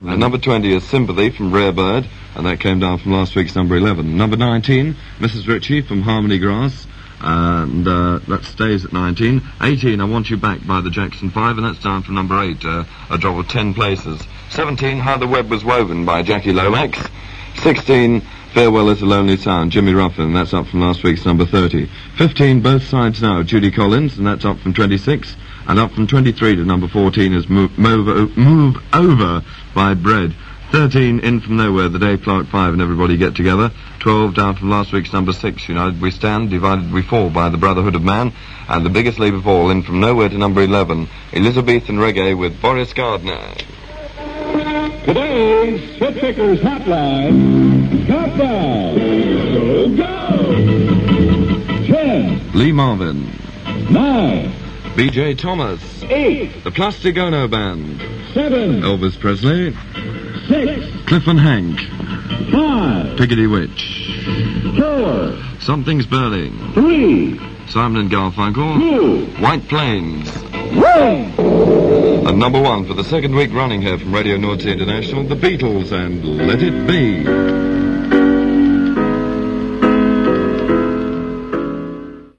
counts down the new Top 20 on RNI